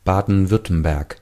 Ääntäminen
Ääntäminen Tuntematon aksentti: IPA: [ˈbaːdn̩ˈvʏrtəmbɛrk] IPA: /ˈbaːdənˈvʏrtəmbɛrk/ Lyhenteet ja supistumat Ba-Wü Haettu sana löytyi näillä lähdekielillä: saksa Käännös Erisnimet 1. Baden-Württemberg Artikkeli: das .